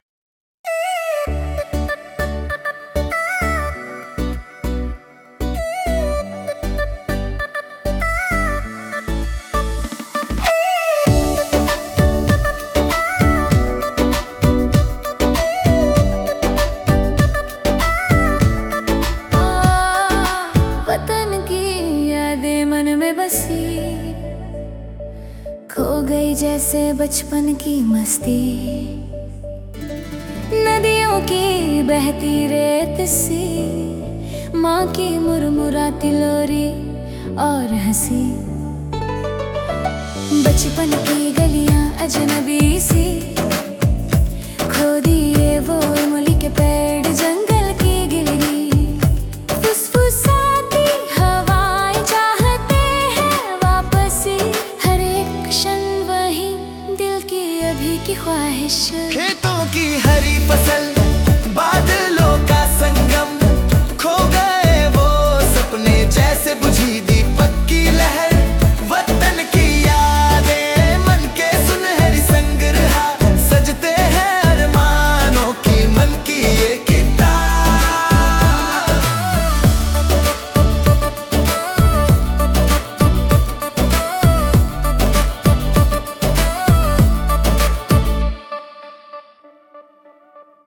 Hindi Bubblegum Dance